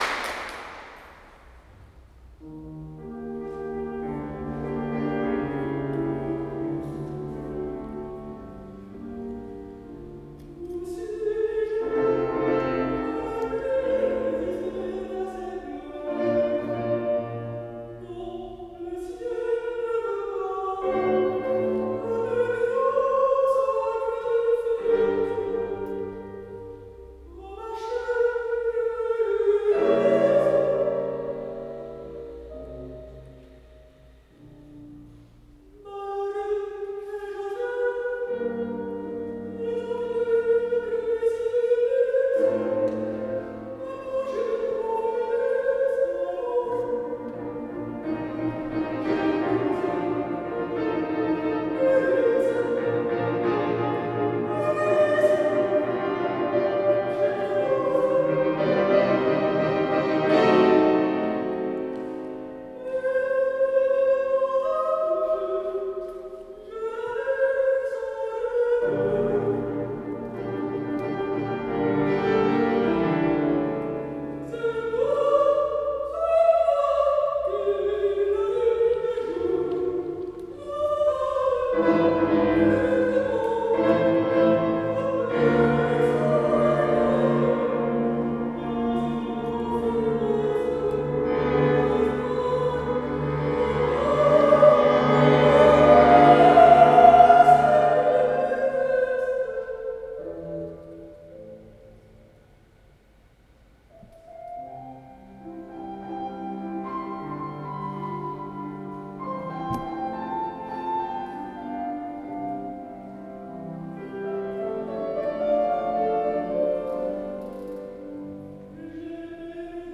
Performance in 2019
August 22, 2019, Rencontres de musique classique et contemporaine de Calenzana, France